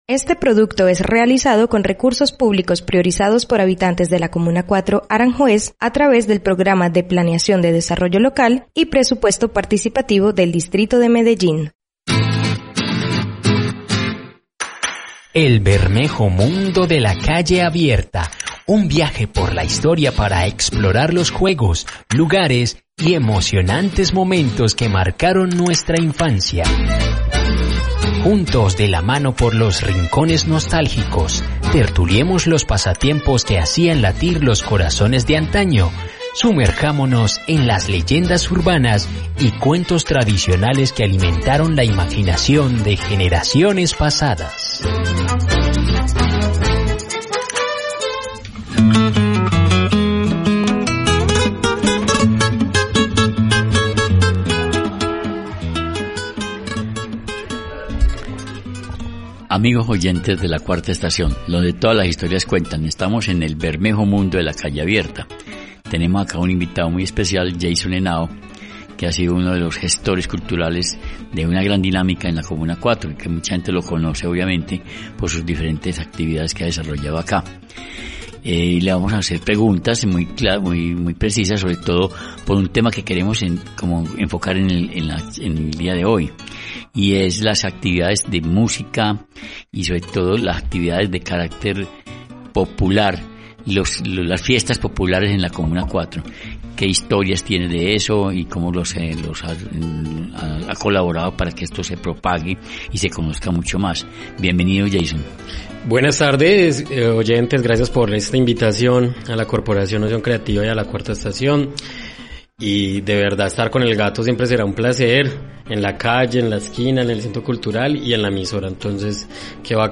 🎺🗣  Conversamos